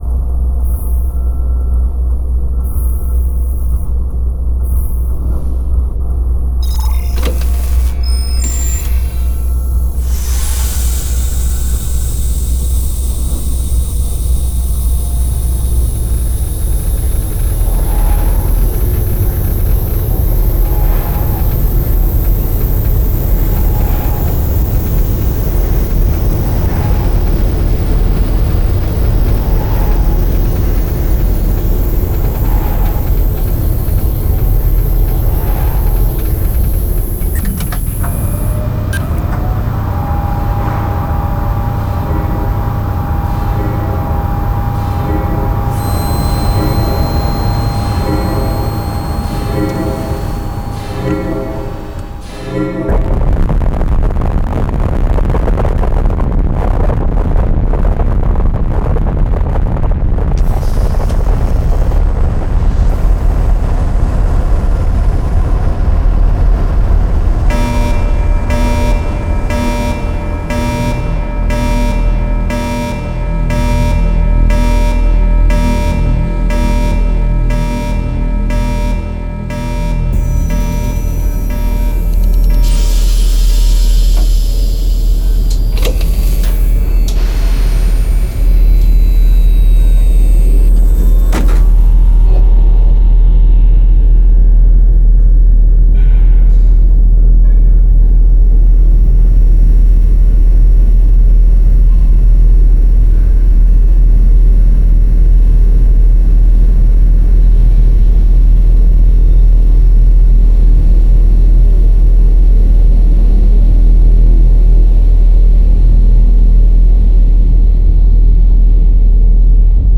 Sci-Fi Sound Effects Packs
Professional sci-fi sound effects packs for movies, video games, and more.